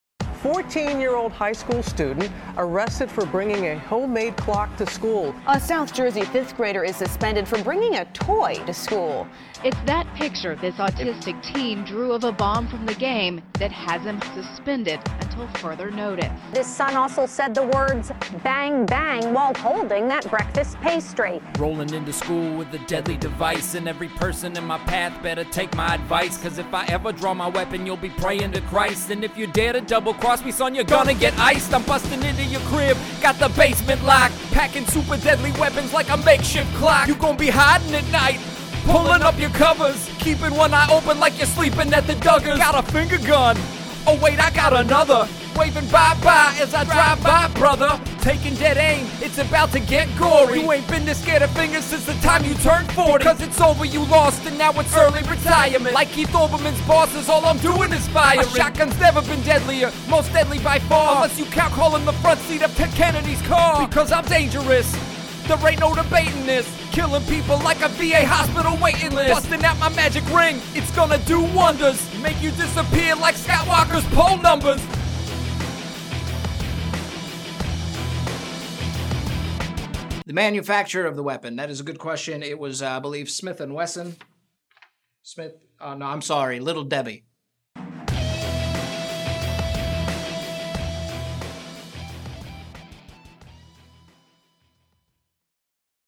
Hardcore rap meets zero tolerance.